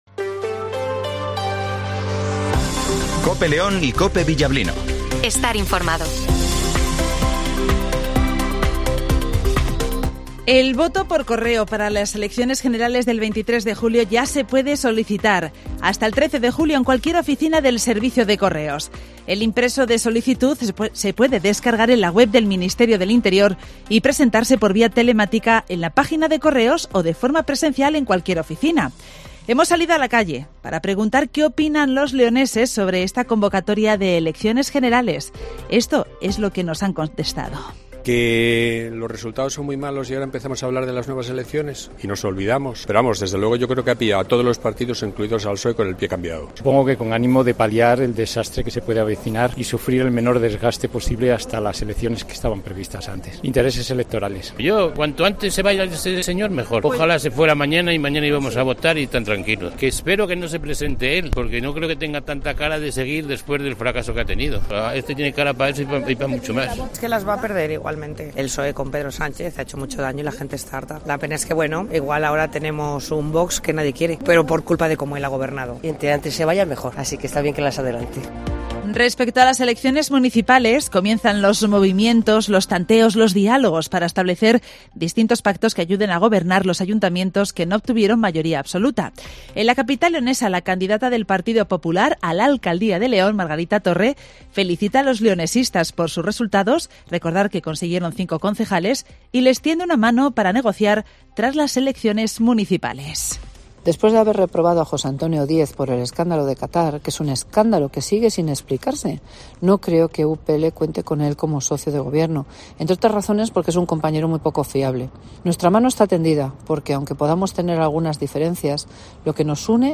Escucha la información matinal de las 08:20 h en Cope León 31-05-23
Redacción digital Madrid - Publicado el 31 may 2023, 08:20 - Actualizado 31 may 2023, 14:52 1 min lectura Descargar Facebook Twitter Whatsapp Telegram Enviar por email Copiar enlace - Informativo Matinal 08:20 h